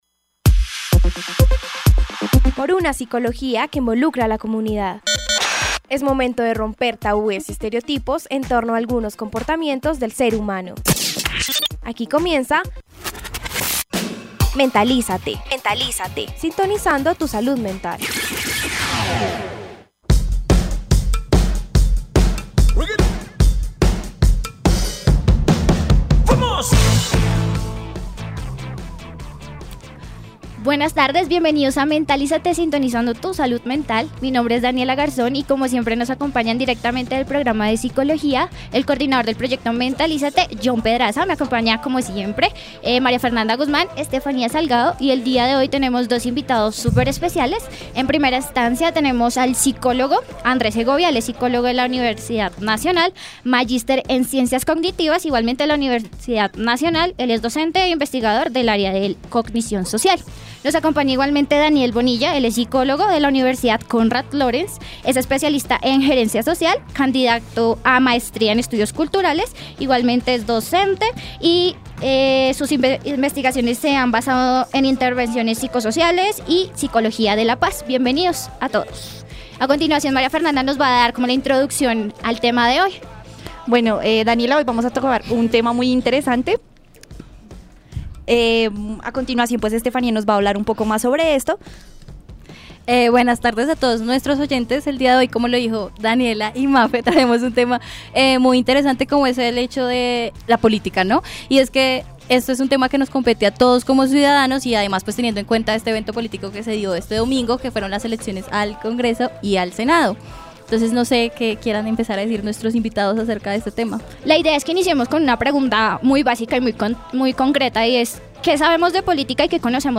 Mentalízate contó con la participación un líder social, quien contó de qué forma ayudó a su comunidad a informarse, logrando movilizarla hacia el voto.